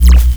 sci-fi_electric_pulse_hum_01.wav